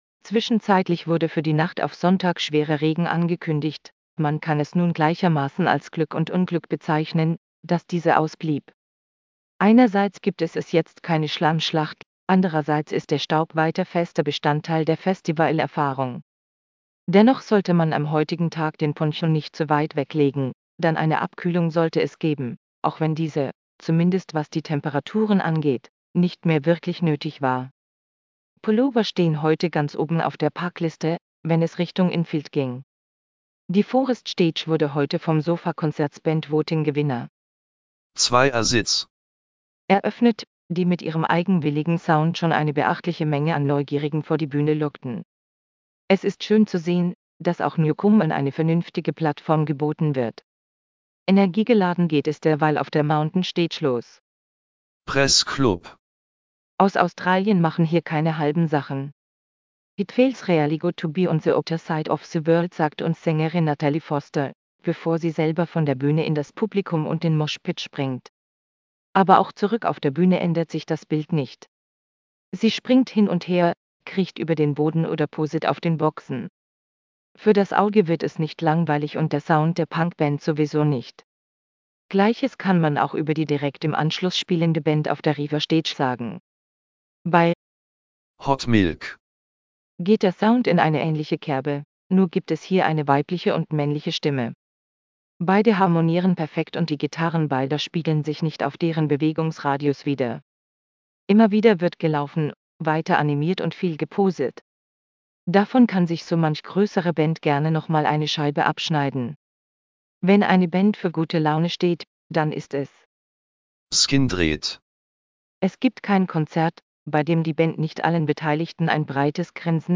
Lass Dir den Beitrag vorlesen